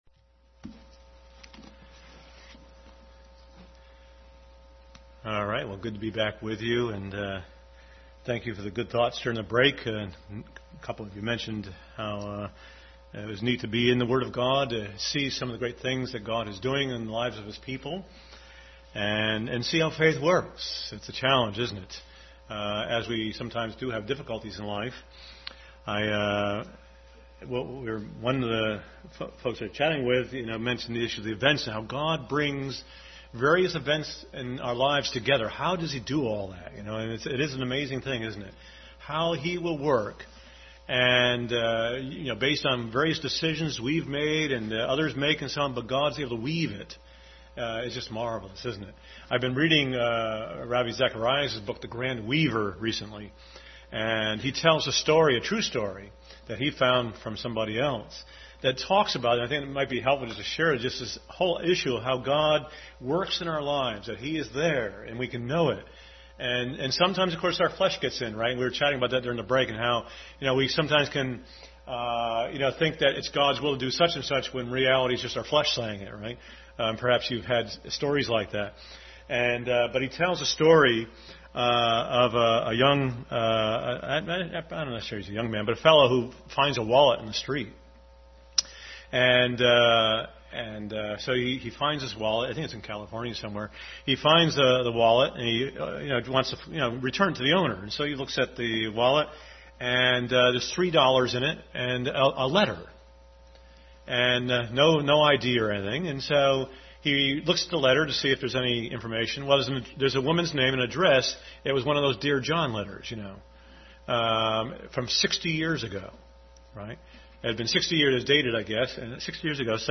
Hebrews 11:11-31 Service Type: Family Bible Hour Bible Text